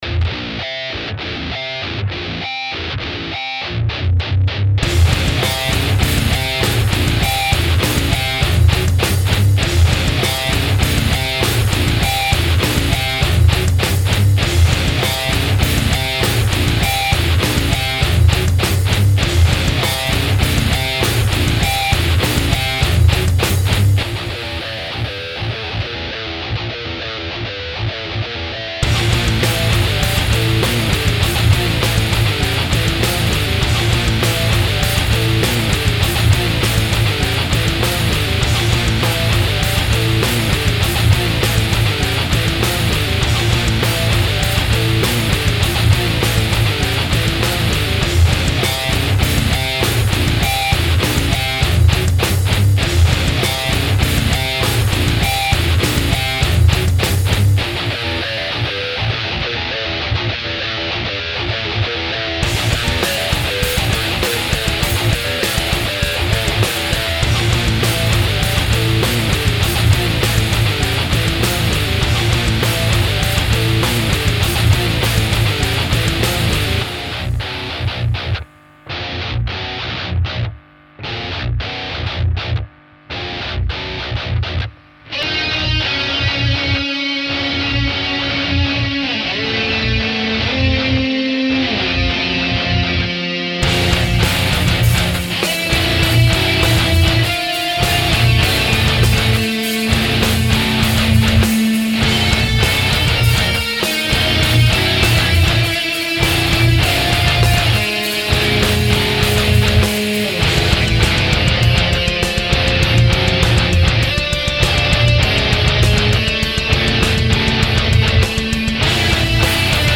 Un bundle de "gros sons" Metal pour PodXT!
2 samples bien metal